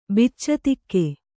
♪ biccatikke